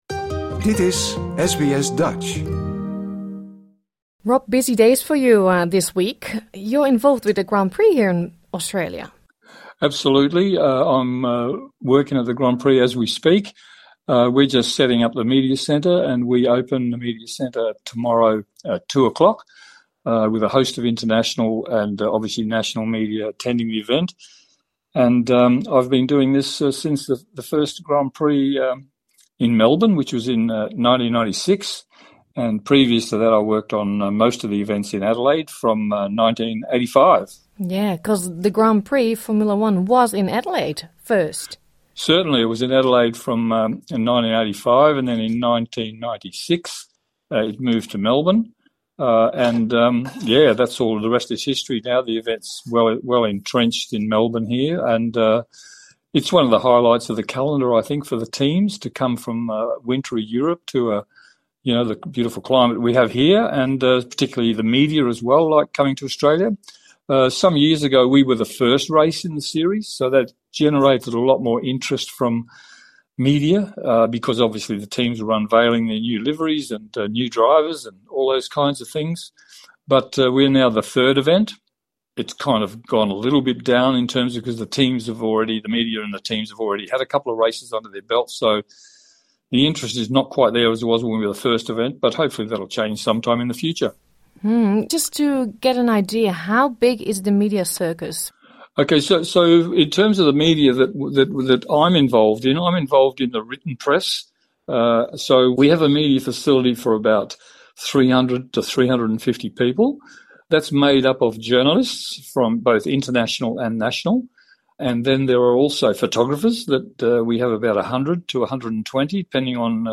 Dit interview is in het Engels.